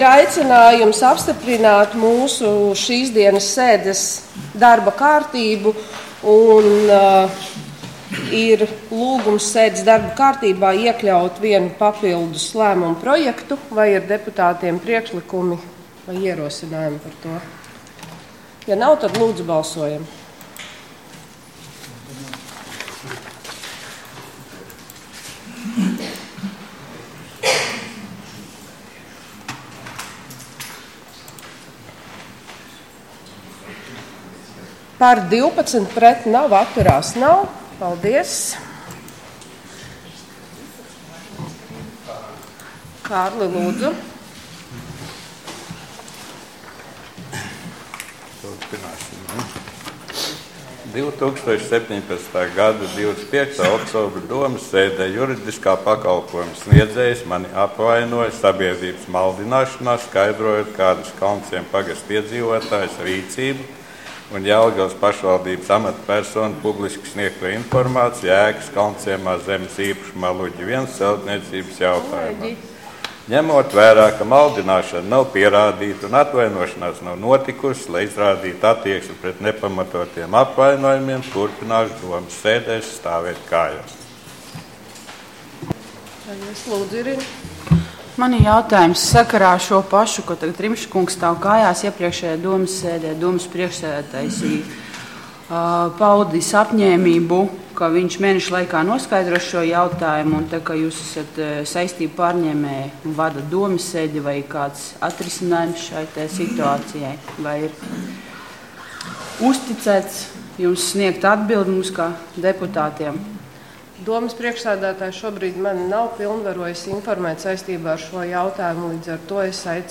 Domes sēde Nr. 10